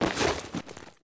magpouch.ogg